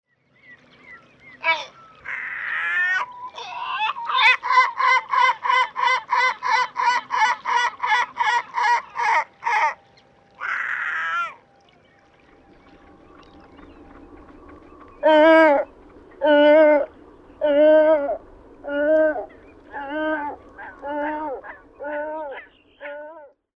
Merilokki
Larus marinus
Ääni: Syvä ja möreä ääni, muistuttaa harmaalokin ääntä.